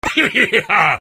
Heheheha Effect
heheheha-effect.mp3